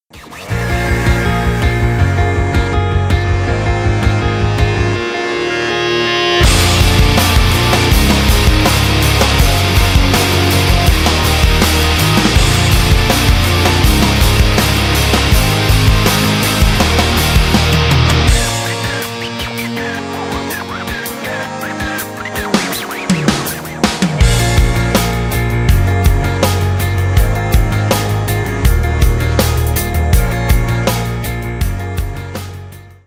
Рок Металл
без слов